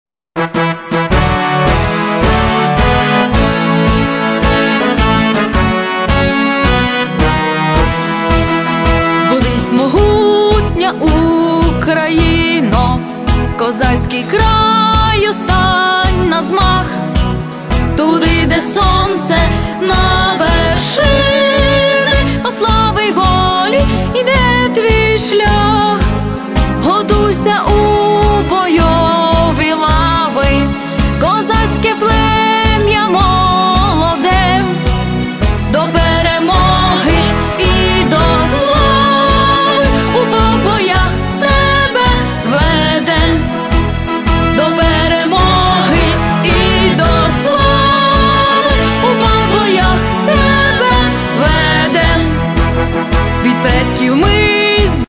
Children Perform Ukrainian Folk And Patriotic Songs